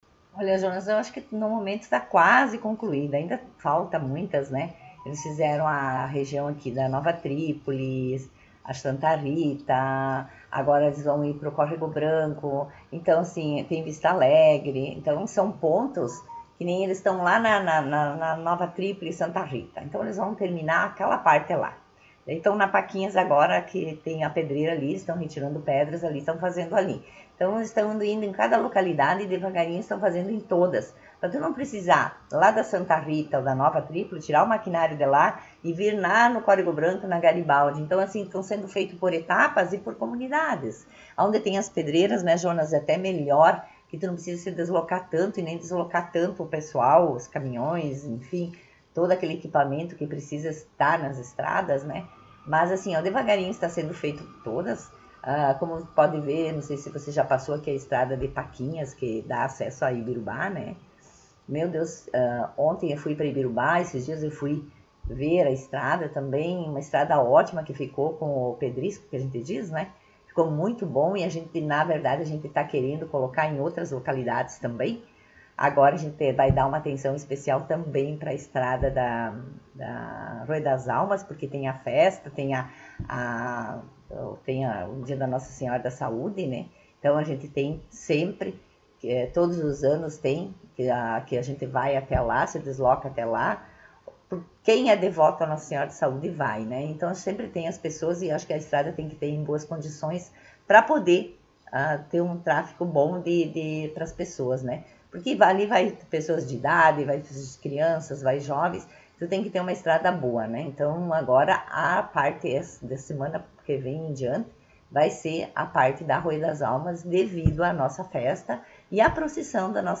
Vice-prefeita Marta Mino concedeu entrevista